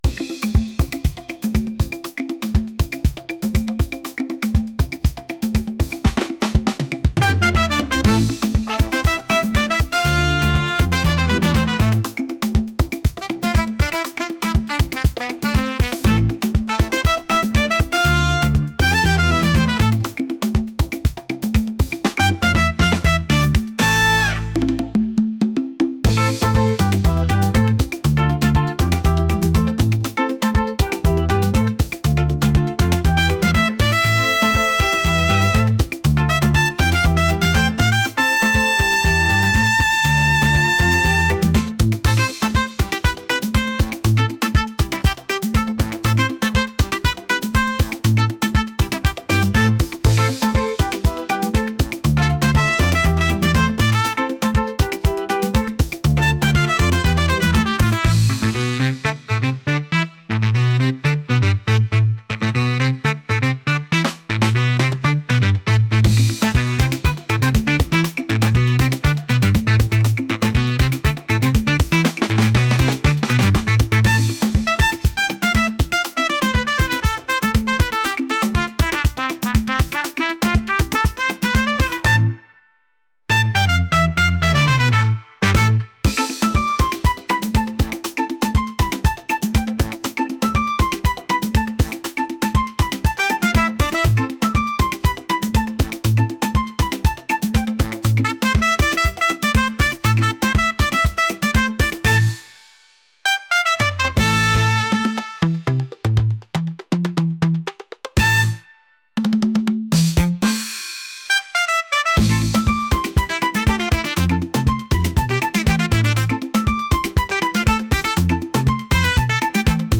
energetic | rhythmic